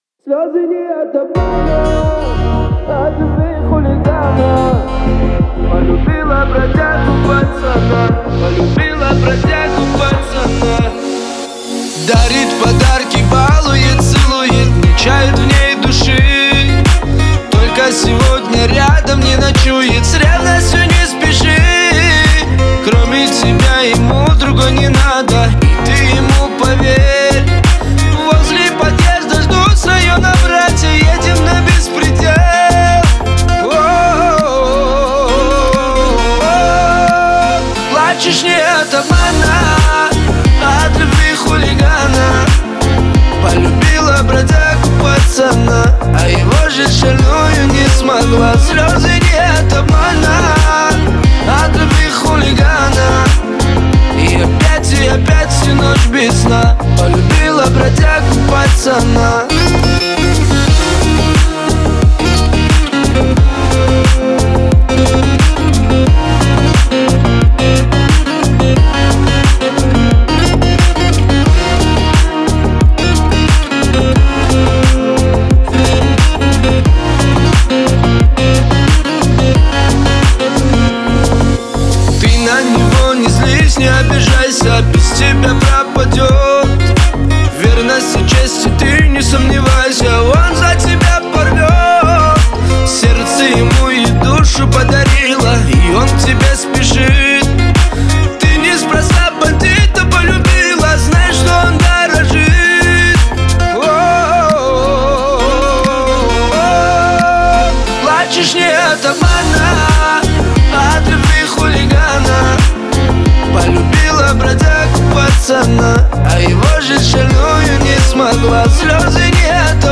• Категория:Кавказская музыка 2024